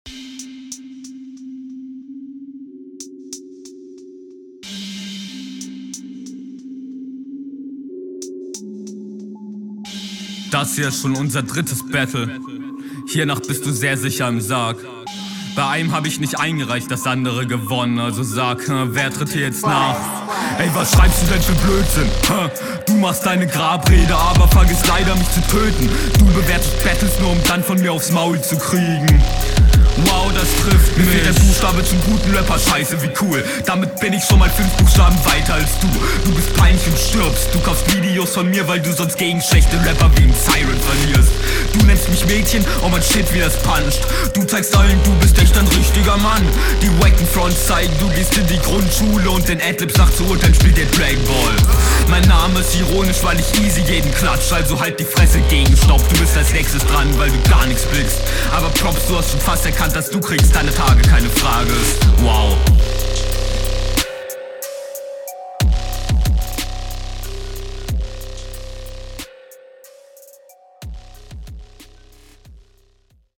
Die Patterns etwas weniger abwechslungsreich, trotzdem sehr gut, aber viel sauberer umgesetzt.